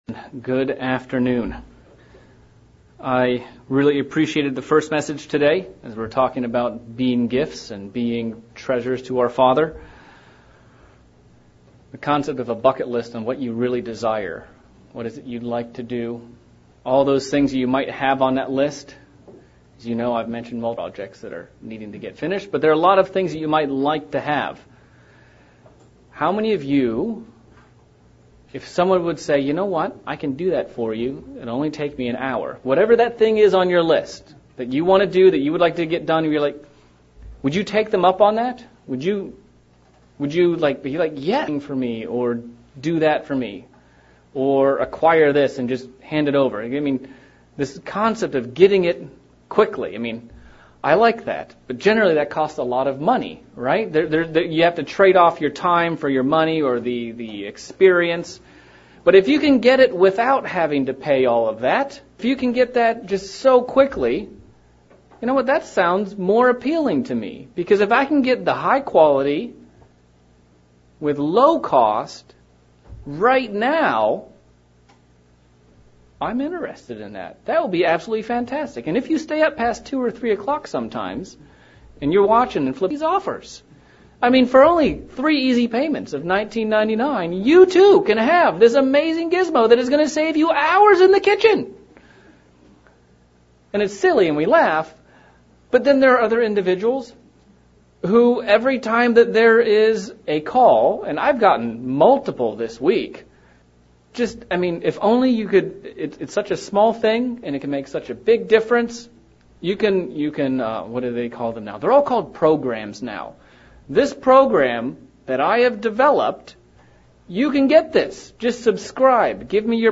Sermon looking at the subject of graft and how it's used Biblically as well as God grafting saints into his flock.